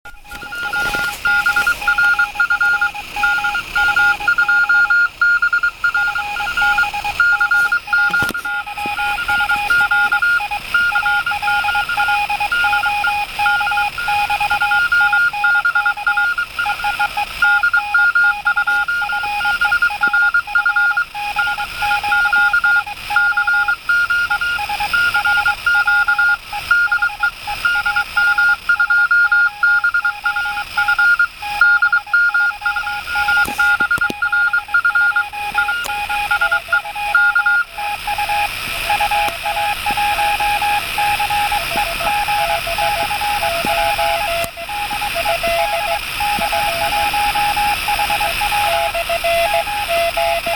Наконец-то удалось в рандеву провести несколько QSO из леса.